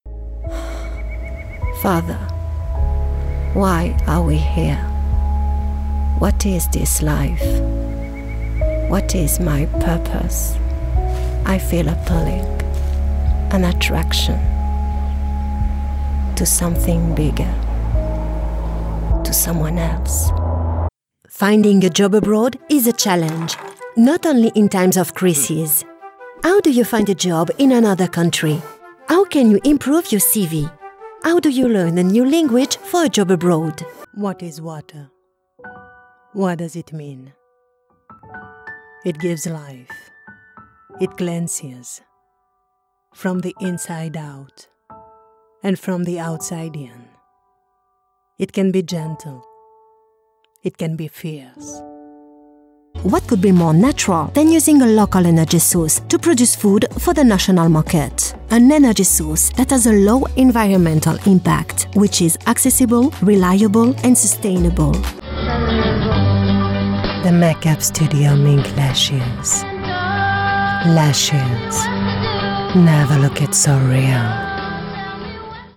Englische Synchronsprecher mit ausländischem Akzent | Voice Crafters